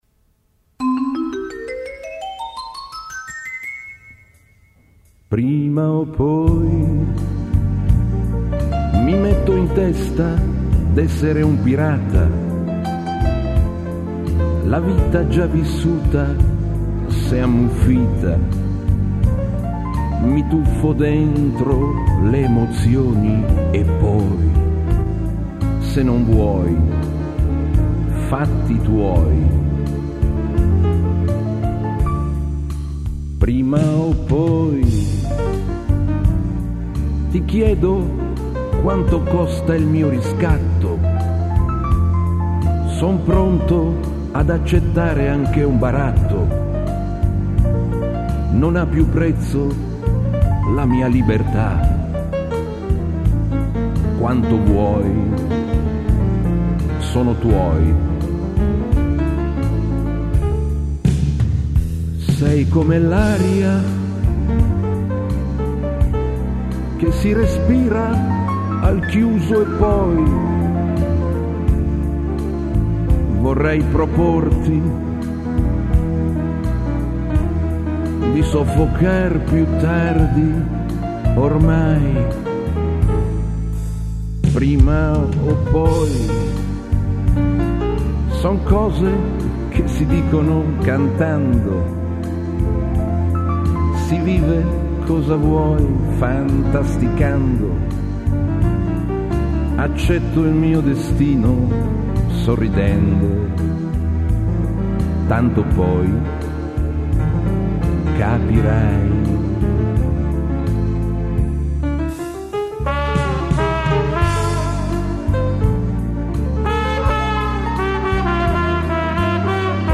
nella sala di registrazione